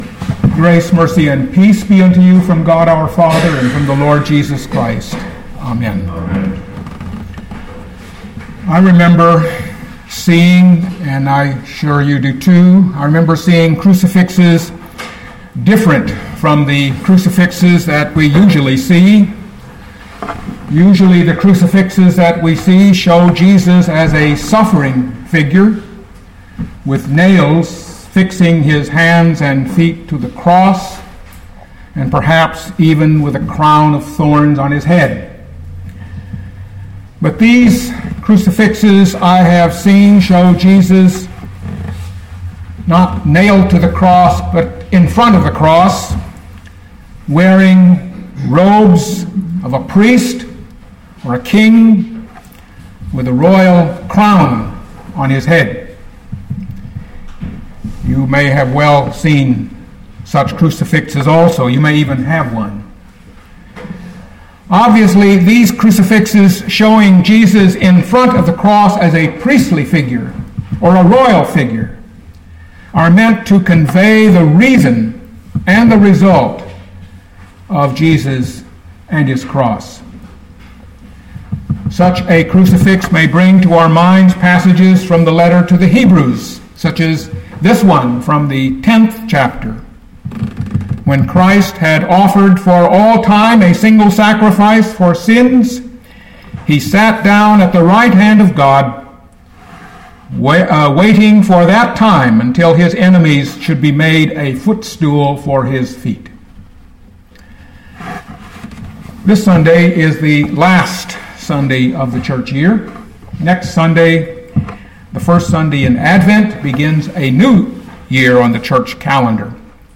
2013 Luke 23:27-43 Listen to the sermon with the player below, or, download the audio.